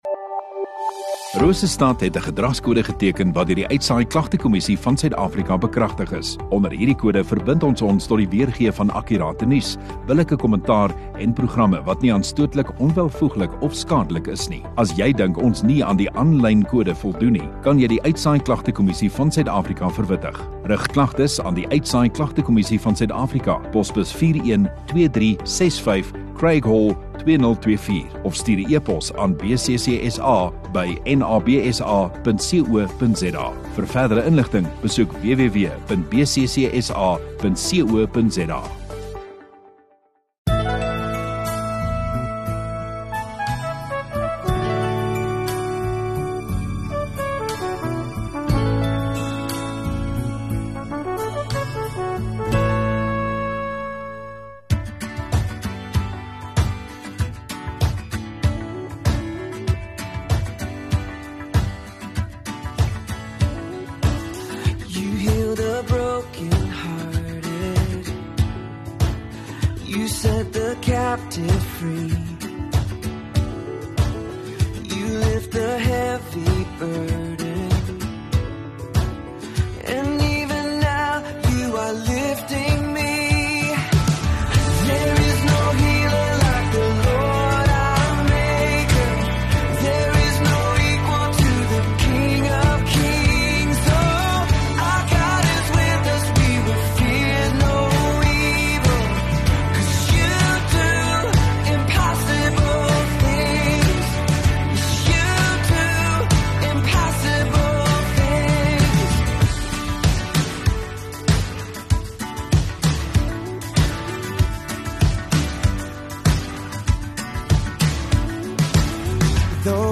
23 Mar Saterdag Oggenddiens